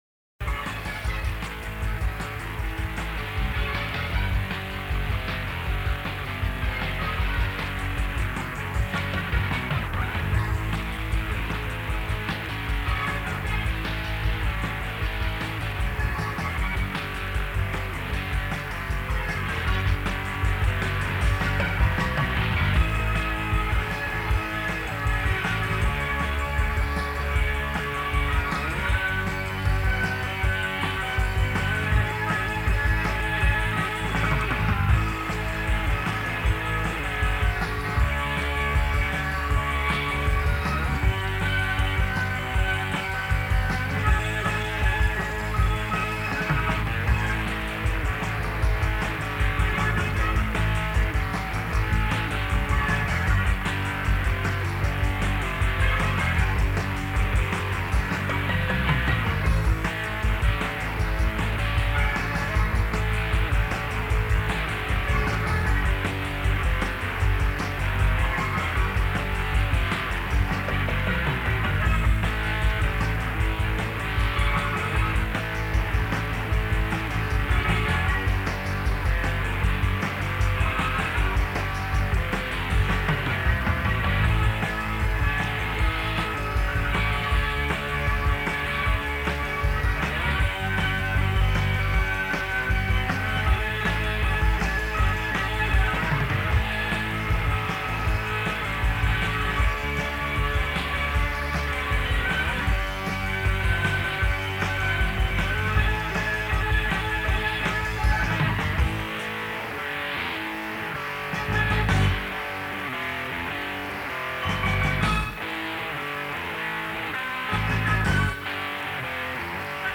Guitar
Keyboards
Bass Guitar
Drums